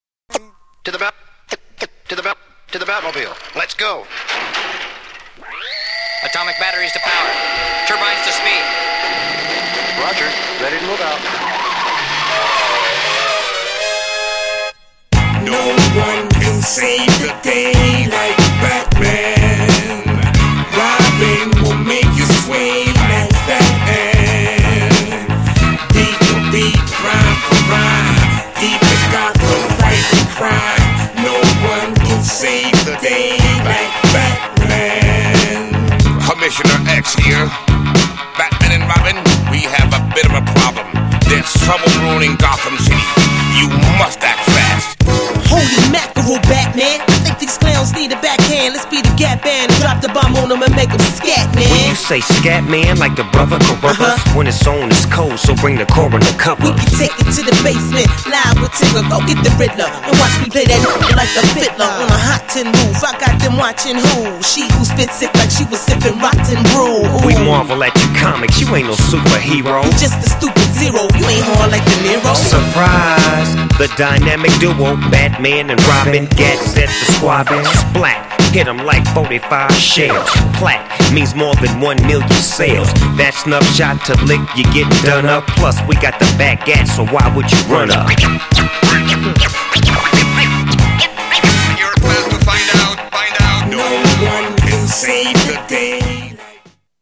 GENRE Hip Hop
BPM 96〜100BPM
DOPEなビート